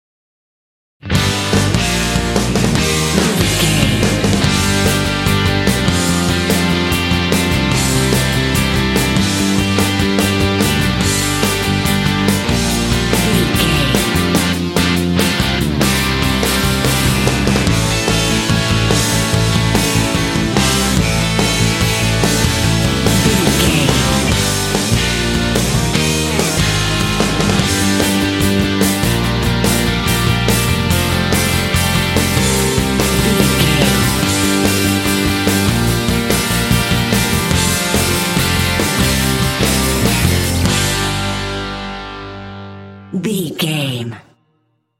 Aeolian/Minor
bouncy
happy
groovy
electric guitar
bass guitar
drums
alternative rock